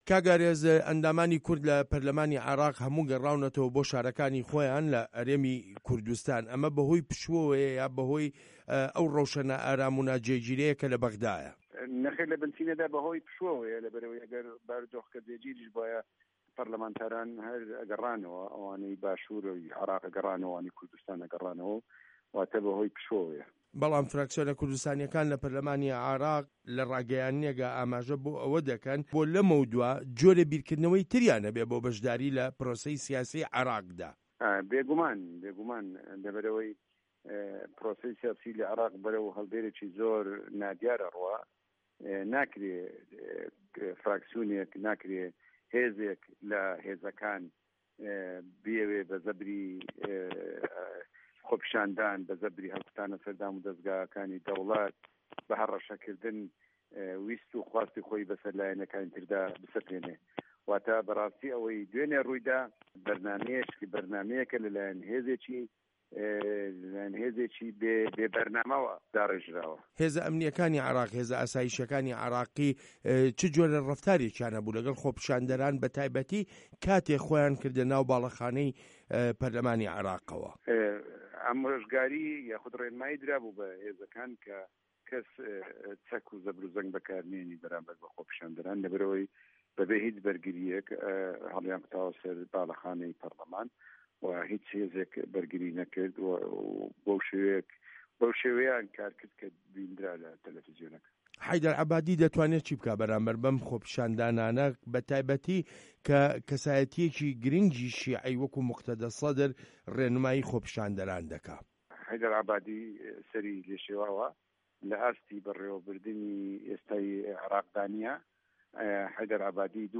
هه‌رێمه‌ کوردیـیه‌کان - گفتوگۆکان
وتووێژ لەگەڵ ئارێز عەبدوڵا